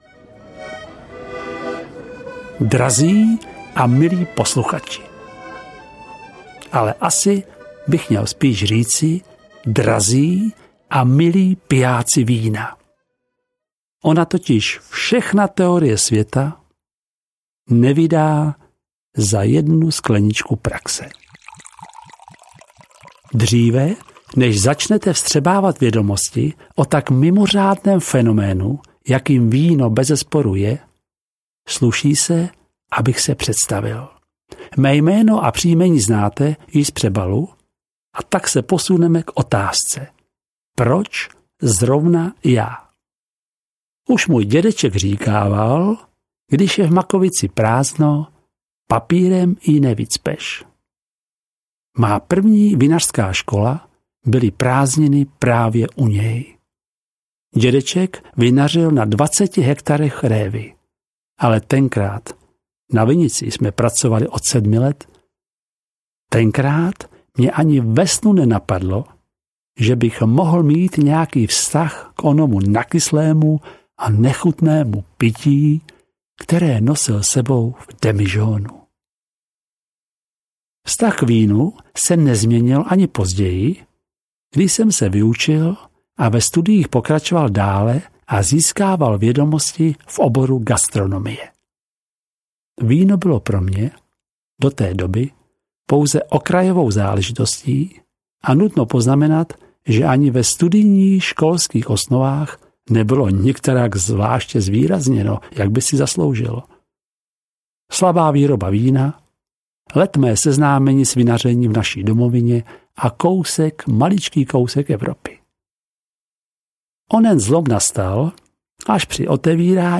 Obdivuhodný svět vín 1 audiokniha
Ukázka z knihy